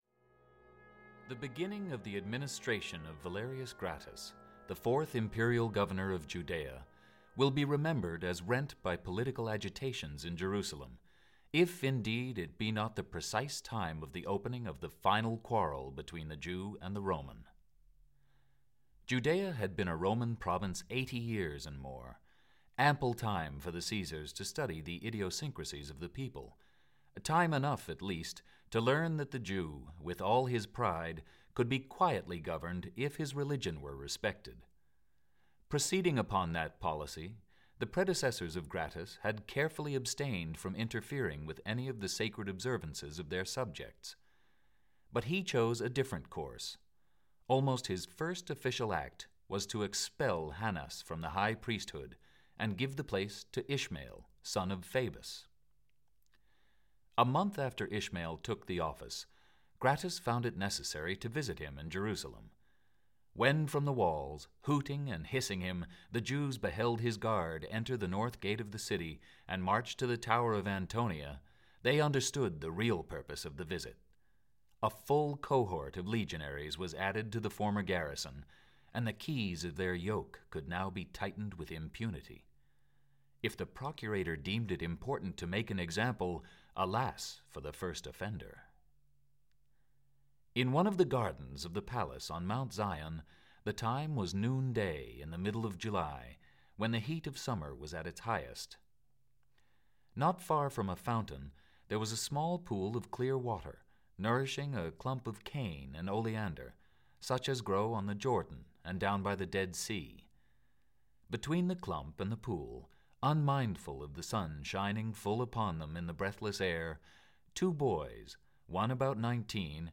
Ben Hur (EN) audiokniha
Ukázka z knihy